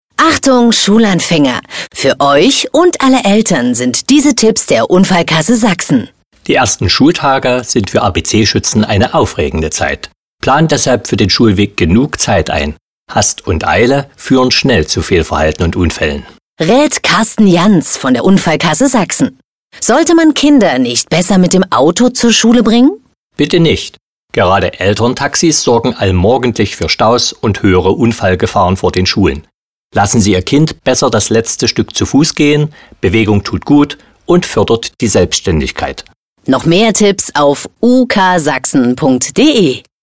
Spot der Unfallkasse zum Sicheren Schulweg